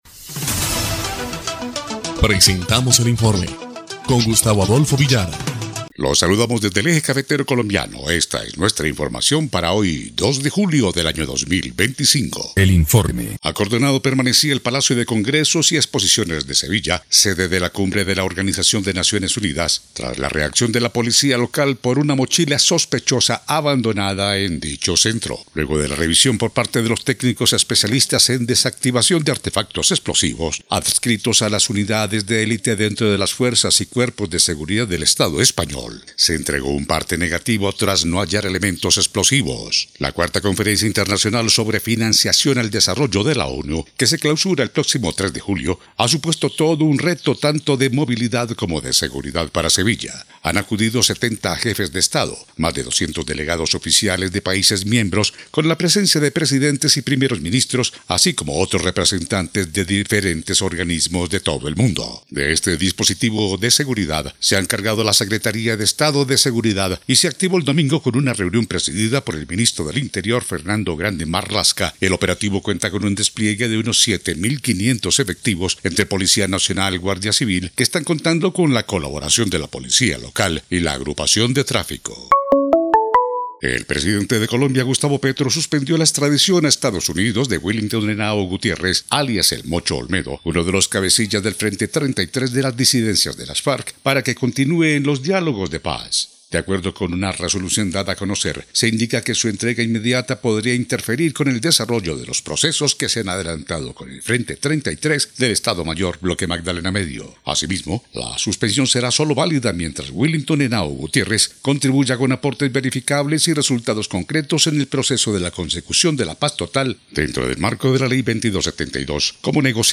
EL INFORME 1° Clip de Noticias del 2 de julio de 2025